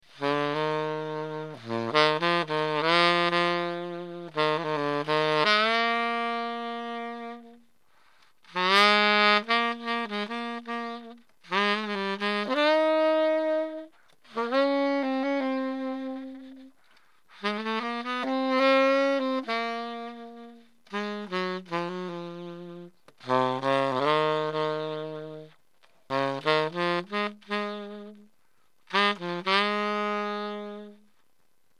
この時代ならではの古いハードラバー素材の味が音色にも良く出ます。
サンプル音源　IWサテンテナー